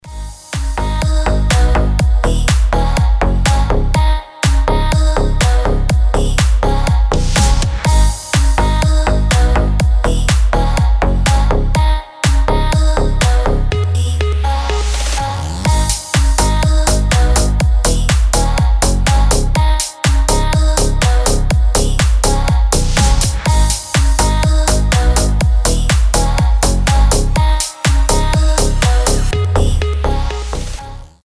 • Качество: 320, Stereo
ритмичные
Club House
Легкий клубный вайб